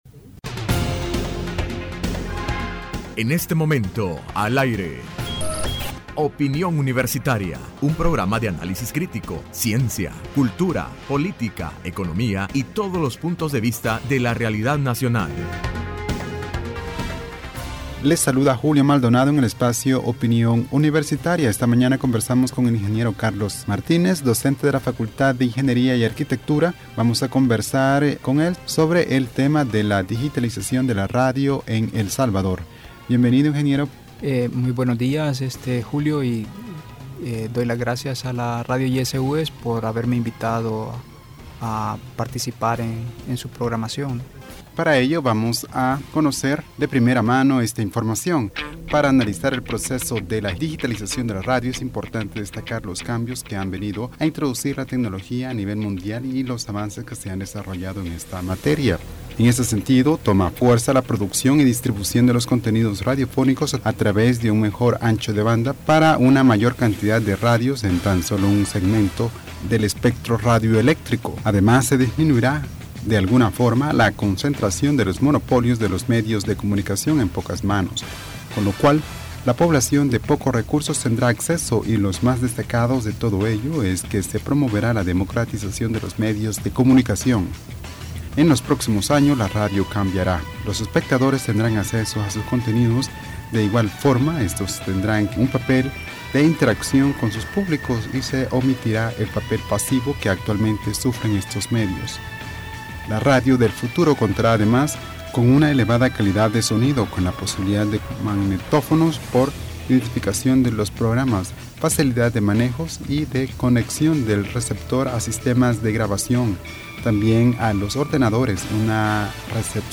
Entrevista Opinión Universitaria (18 de Agosto 2015): La digitalización de la Radio en El Salvador.
Cómo se desarrollará el proceso de la Digitalización de la Radio y Televisión en El Salvador, ya que para el año 2018 se tiene proyectado dar inicio al apagón analógico. Es lo que puedes escuchar en esta entrevista.